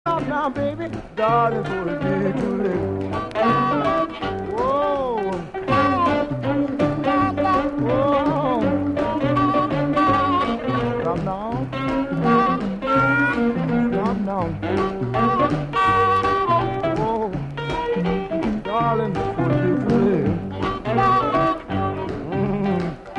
lead guitar
Here is an extract from the second instrumental break.